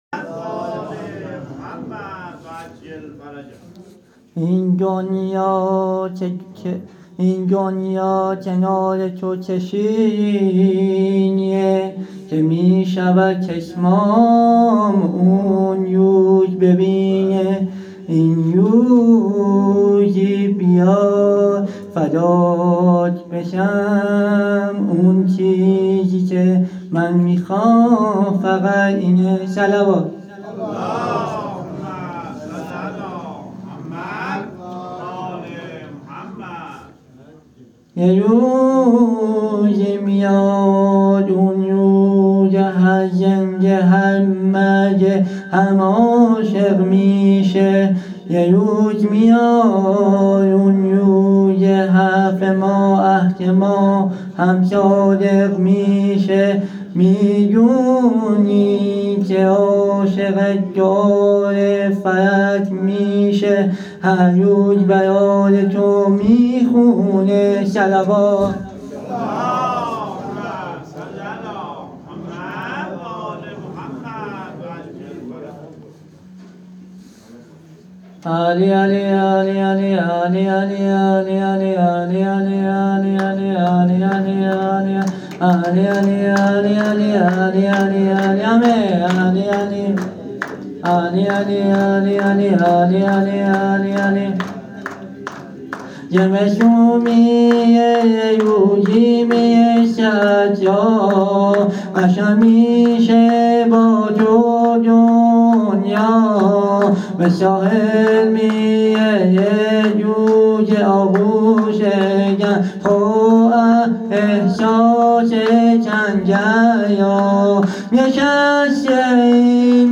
مدح مولودی خوانی
هیئت ثارالله تهران